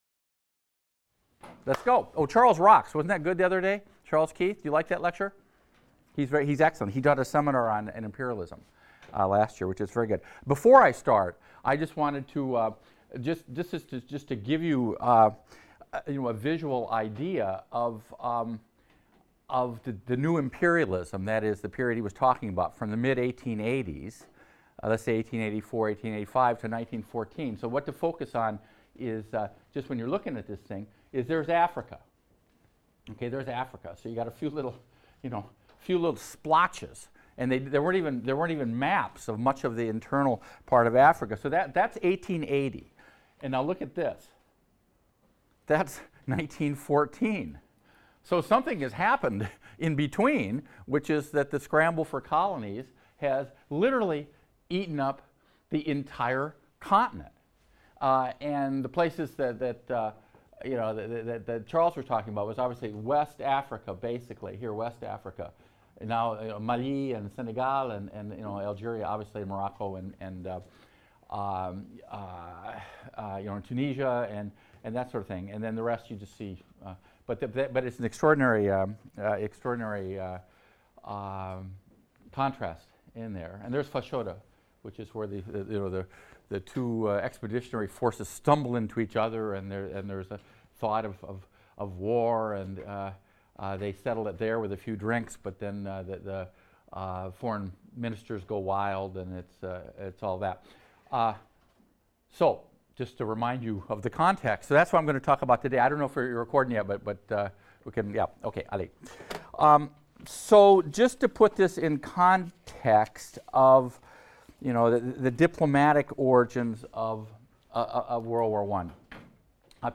HIST 276 - Lecture 13 - The Origins of World War I | Open Yale Courses